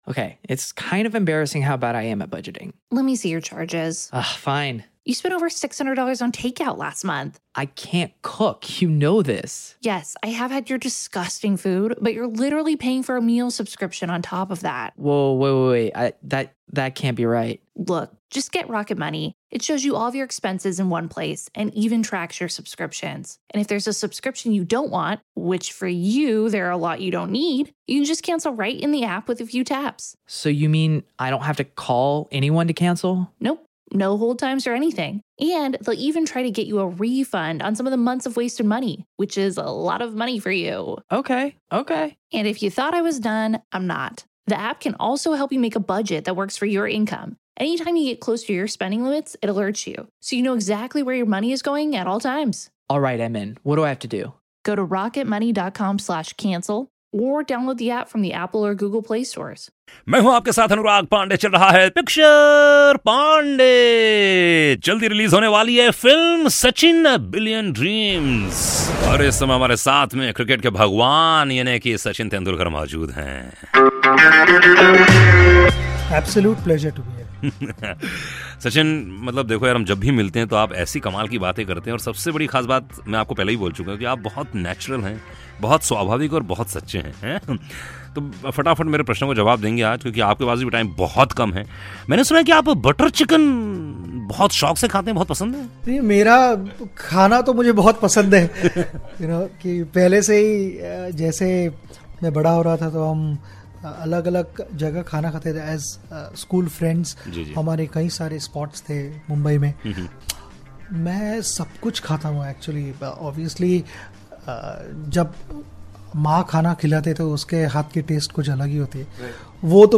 Interview With Sachin For "Sachin: A Billion Dreams" - Link 1